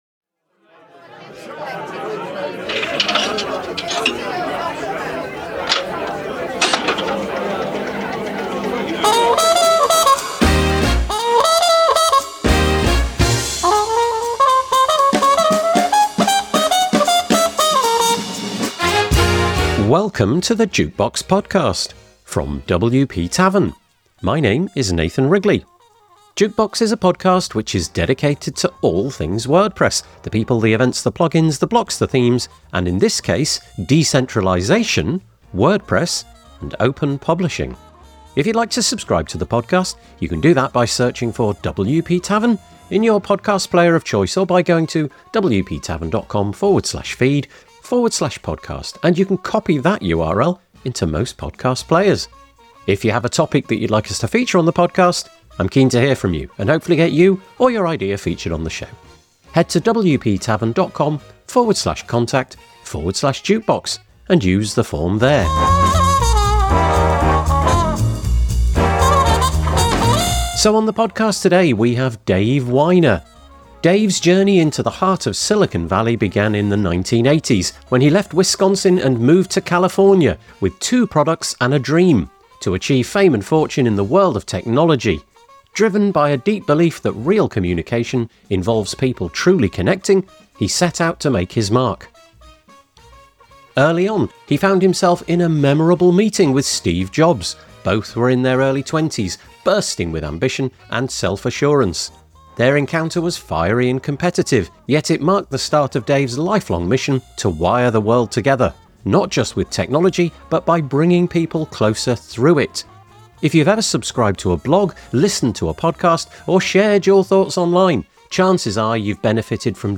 The WP Tavern Jukebox is a podcast for the WordPress community. We interview people who are pushing change in how WordPress evolves. Plugins, Blocks, Themes, Community, Events, Accessibility and Diversity; we try to cover all the bases.